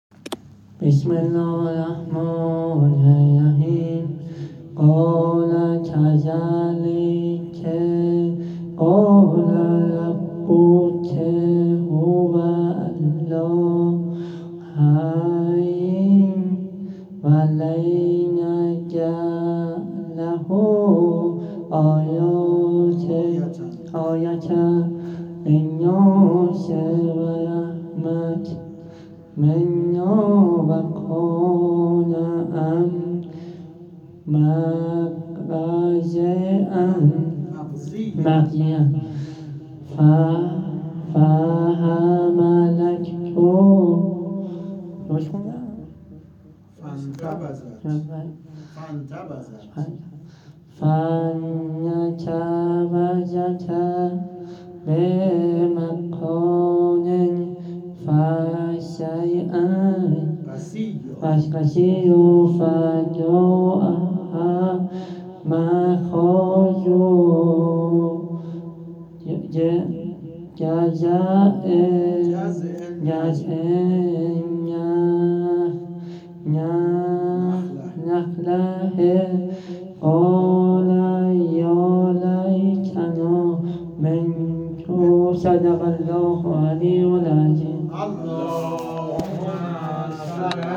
هیئت ابافضل العباس امجدیه تهران